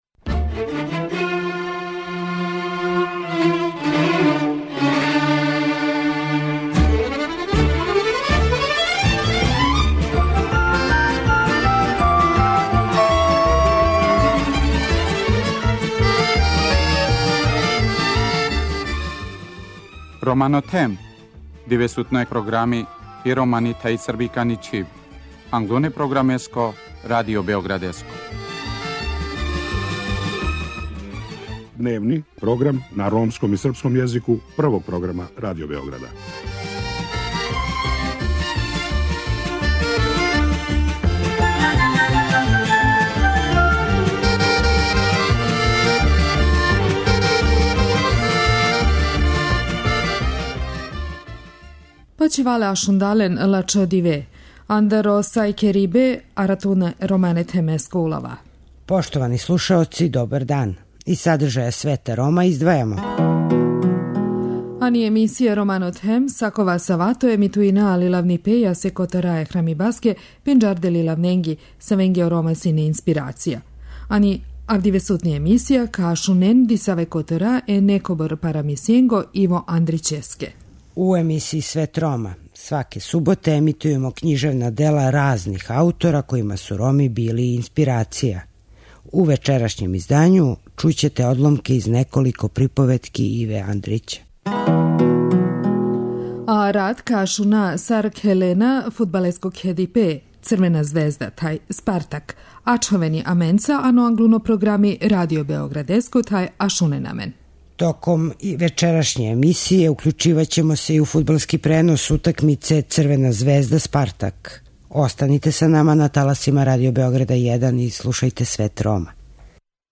У емисији Свет Рома, сваке суботе емитујемо књижевна дела разних аутора којима су Роми били инспирација. У вечерашњем издању чућете одломке из неколико приповетки Иве Андрића.